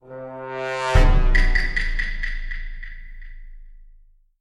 Старт раунда